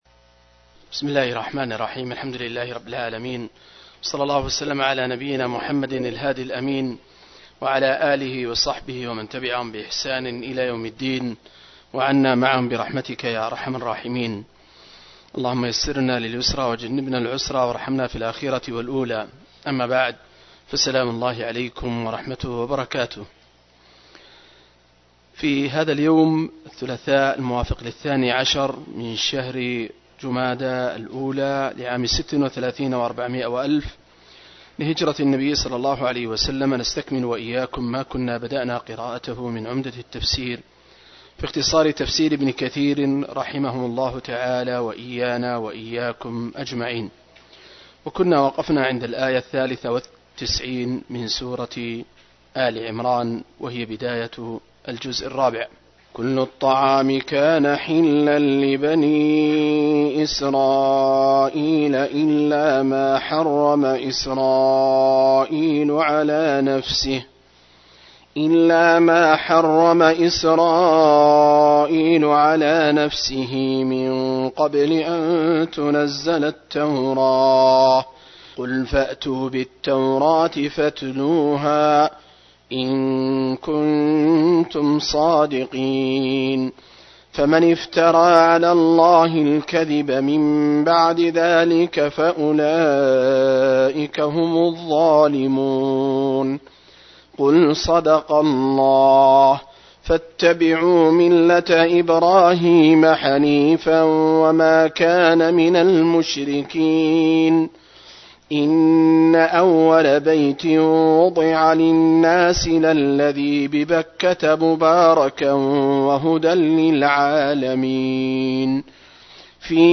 070- عمدة التفسير عن الحافظ ابن كثير رحمه الله للعلامة أحمد شاكر رحمه الله – قراءة وتعليق –